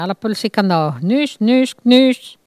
Elle crie pour appeler les canards